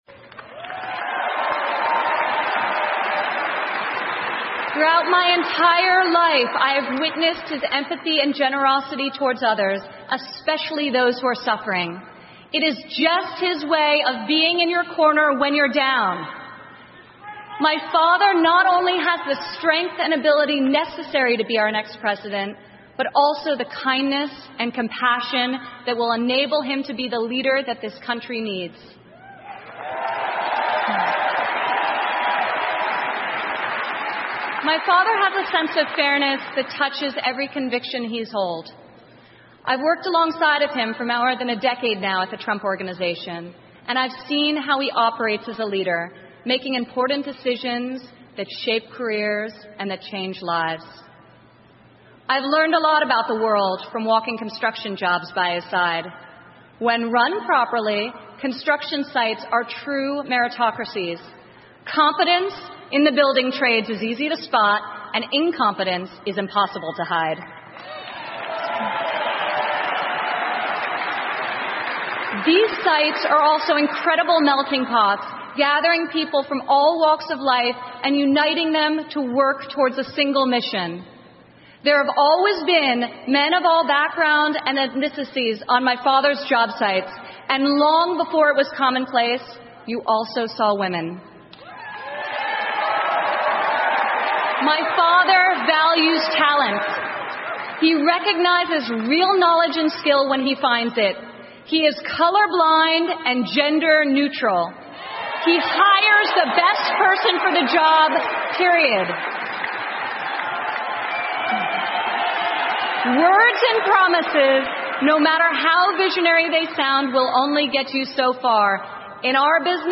美国总统大选演讲 听力文件下载—在线英语听力室